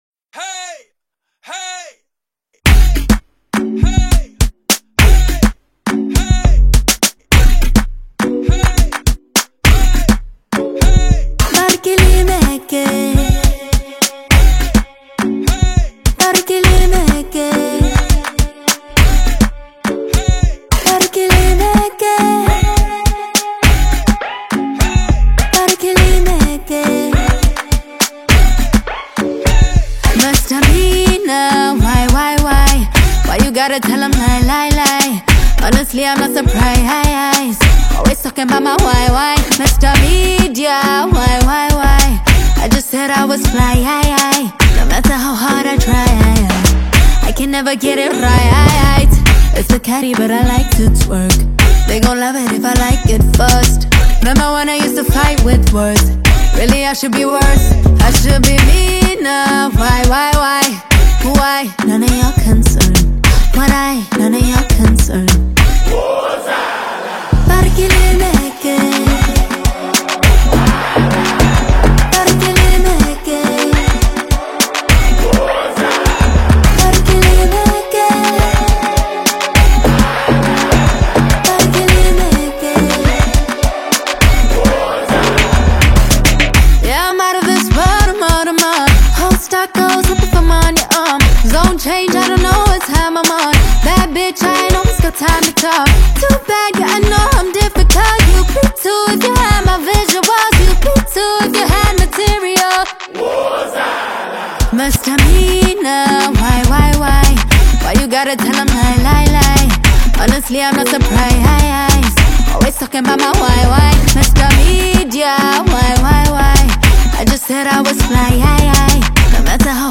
South African female singer and songwriter